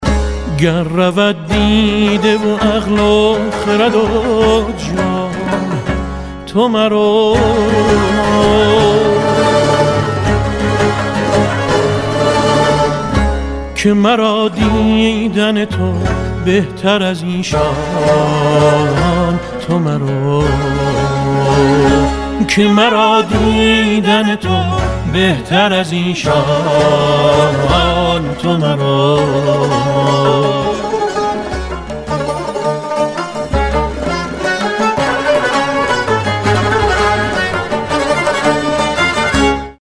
رینگتون با کلام